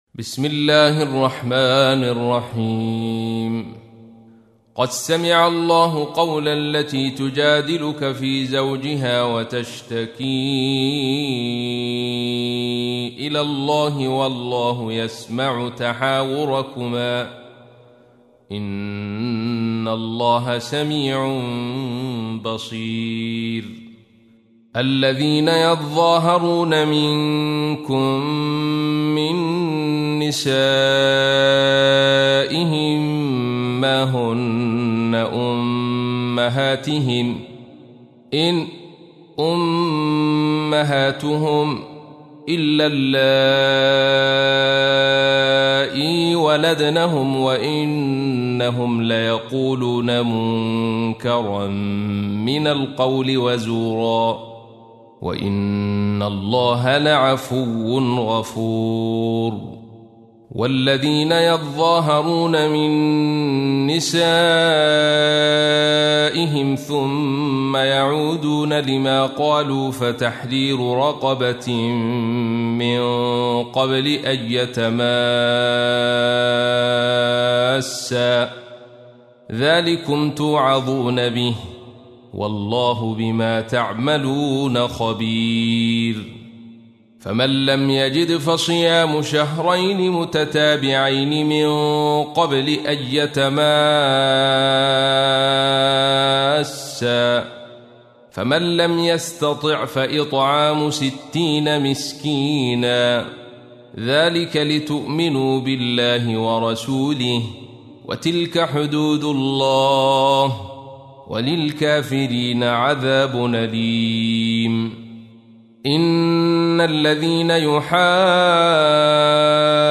تحميل : 58. سورة المجادلة / القارئ عبد الرشيد صوفي / القرآن الكريم / موقع يا حسين